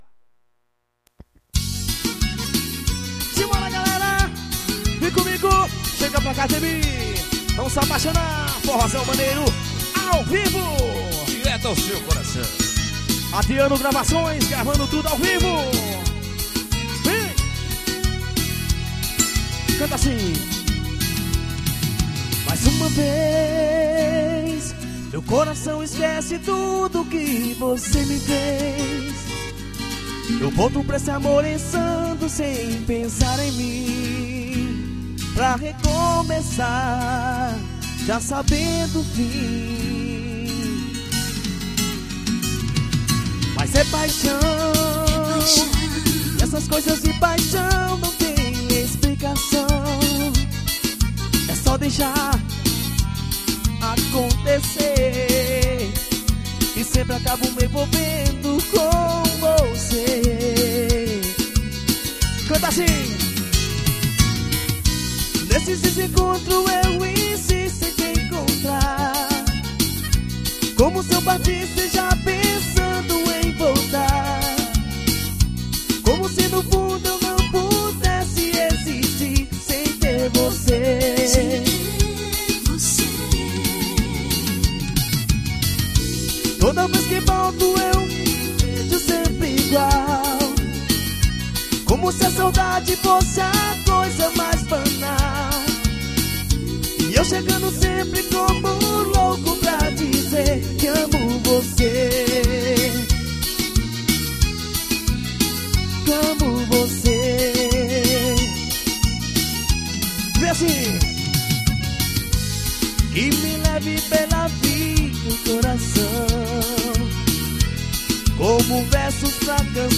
capitacao show ao vivo.